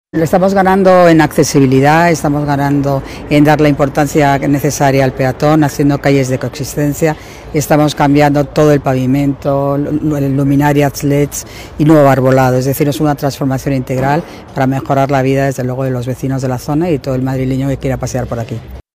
Nueva ventana:Intervención Paloma García Romero